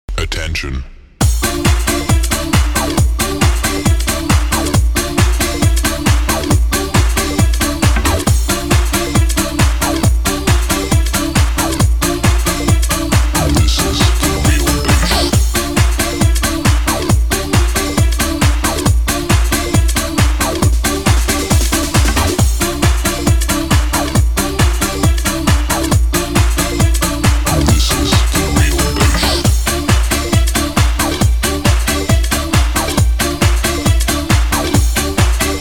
dance
Electronic
club
90-е